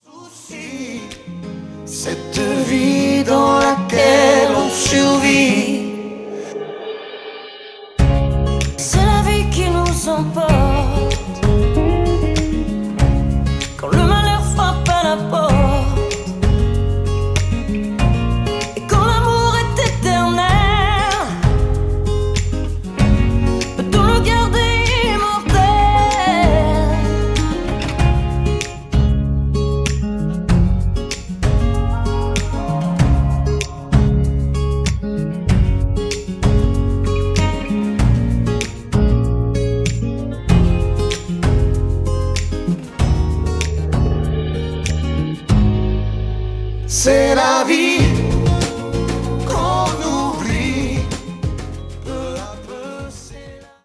avec choeurs et voix...